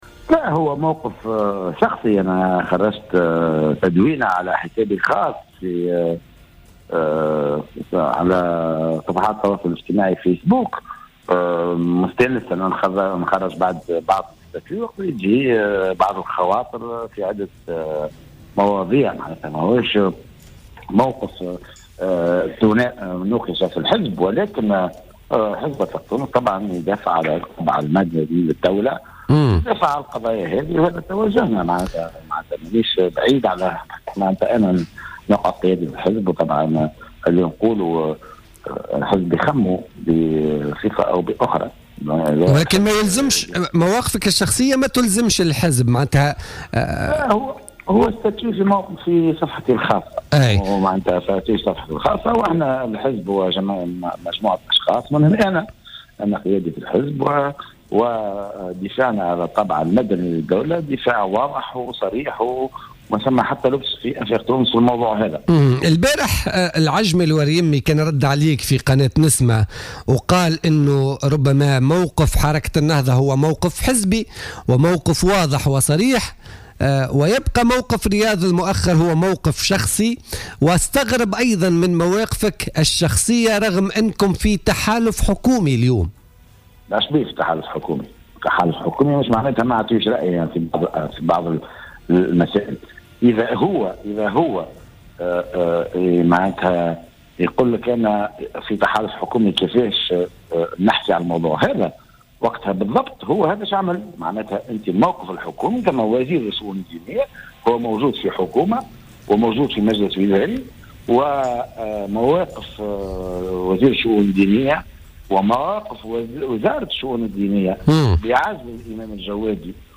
أكد القيادي في حزب آفاق تونس، رياض المؤخر في مداخلة له اليوم في برنامج "بوليتيكا" أن موقفه من إيقاف الإمام رضا الجوادي هو موقف شخصي ولا يمثل موقف حزبه.